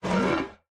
foundry-metal-scrape.ogg